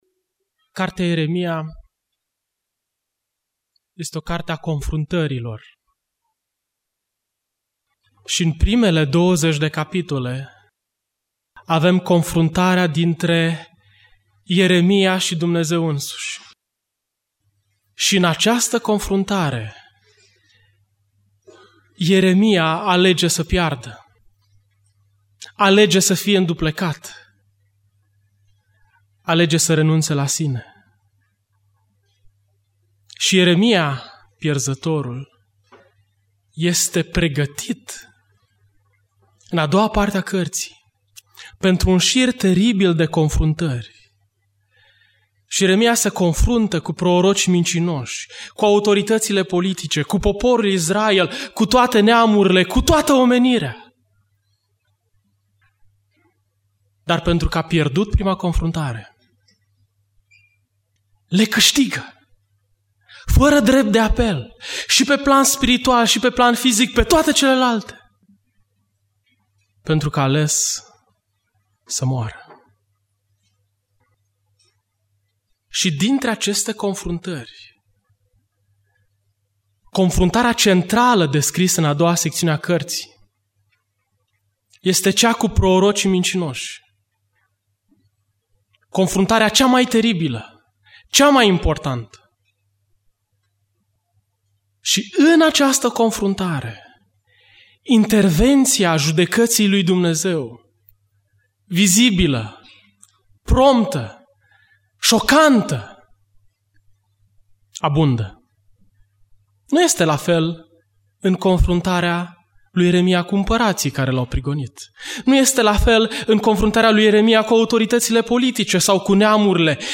Biserica Neemia - Portal materiale - Predica Exegeza Ieremia 28-29